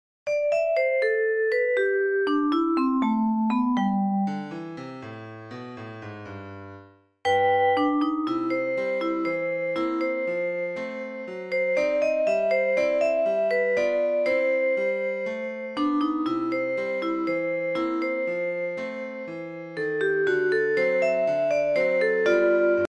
Marblebell game sound music bell#marbleasmr sound effects free download